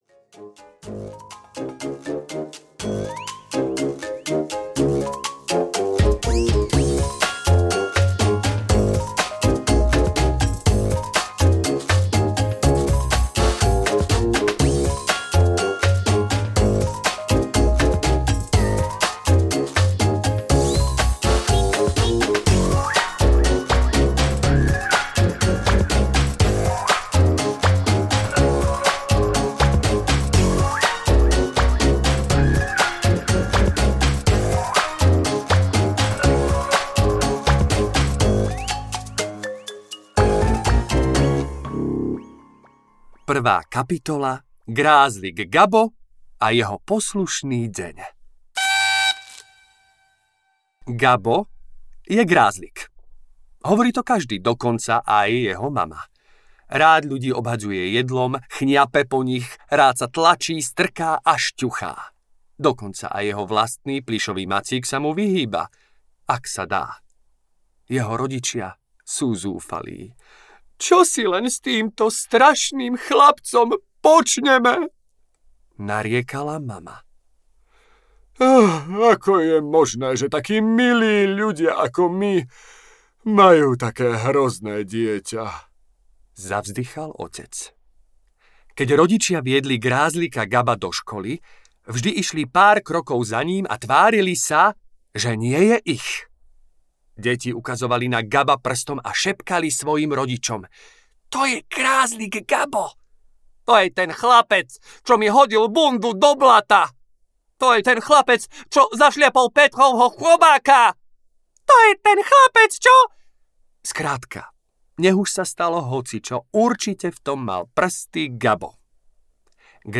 Grázlik Gabo audiokniha
Ukázka z knihy